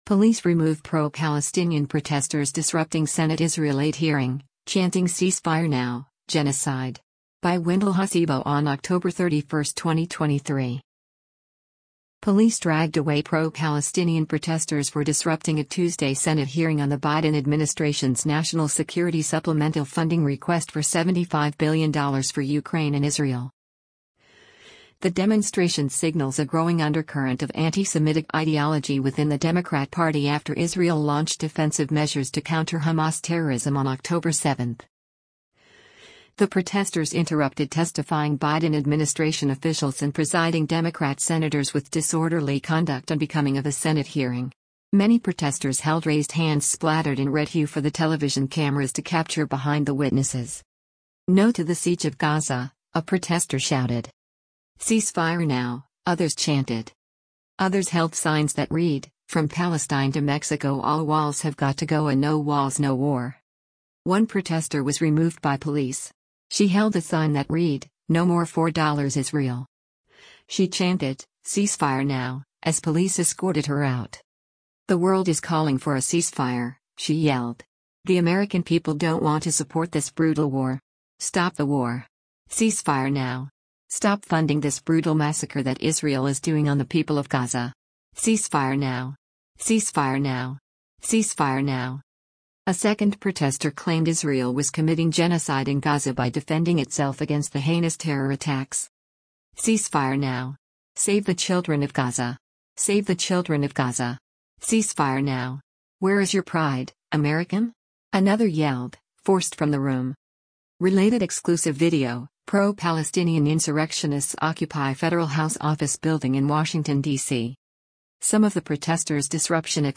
Police Remove Pro-Palestinian Protestors Disrupting Senate Israel Aid Hearing, Chanting ‘Cease-Fire Now,’ ‘Genocide’
“Cease-fire now,” others chanted.
She chanted, “cease-fire now,” as police escorted her out.